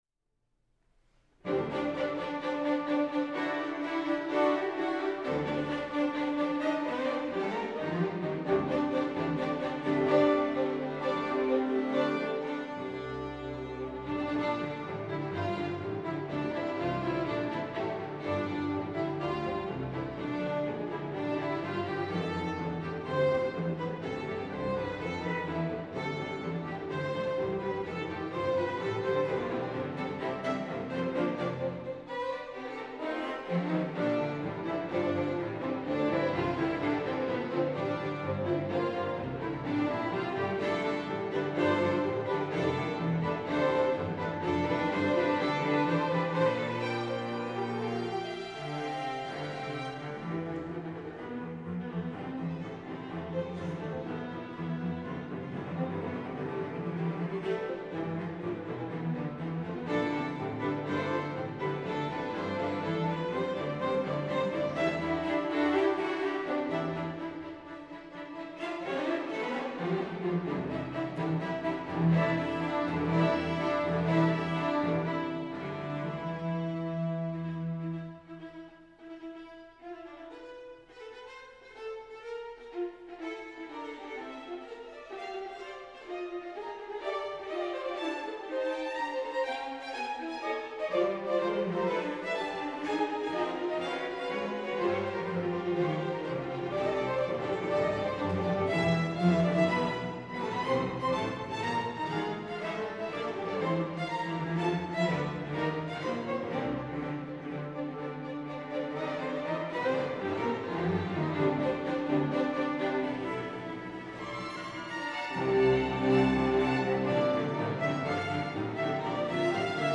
bass May 2018.